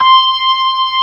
55o-org21-C6.aif